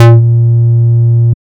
DS_Bass05_A#2.wav